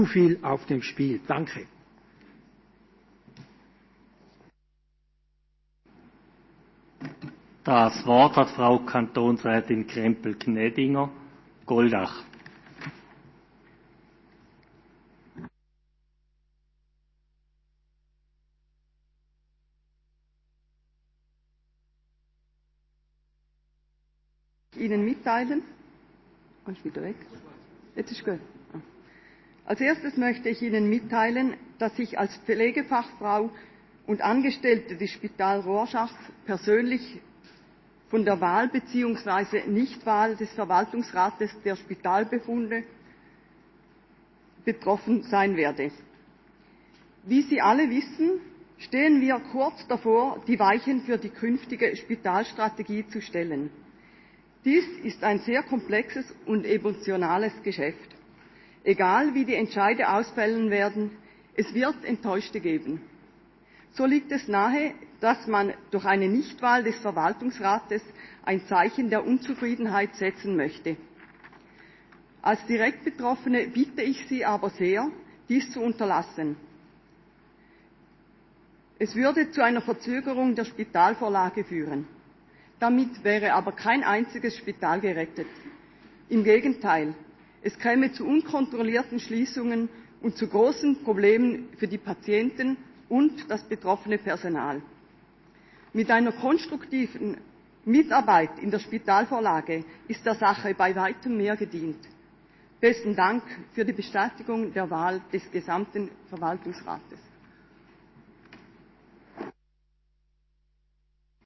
Session des Kantonsrates vom 2. bis 4. Juni 2020